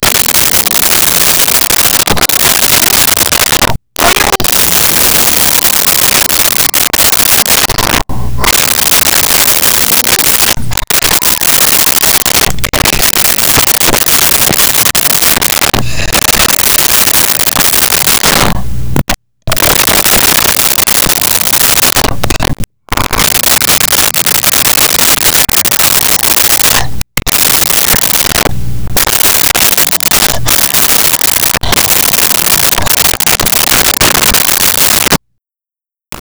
Gorilla Breaths
Gorilla Breaths.wav